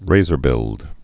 (rāzər-bĭld)